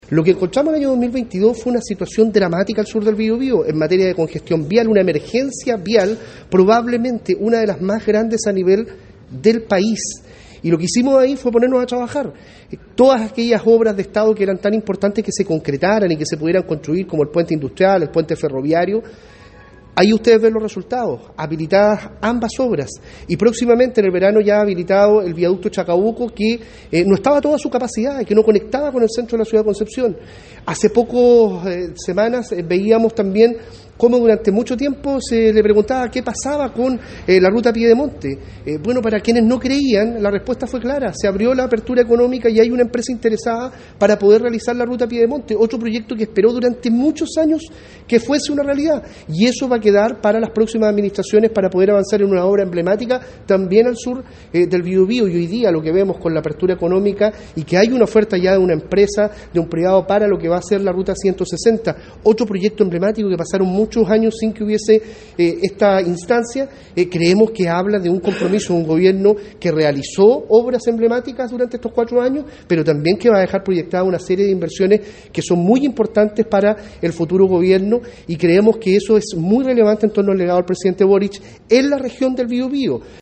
Así lo confirmó el delegado presidencial Eduardo Pacheco, quien relevó la labor de la actual administración y agregó que “vamos a seguir hasta el último día proyectando todos estos hitos emblemáticos porque permiten otorgar una mejor calidad de vida a nuestros compatriotas de la región”.